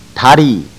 To hear proper pronunciation, click one of the links below
Dah Ree  - Leg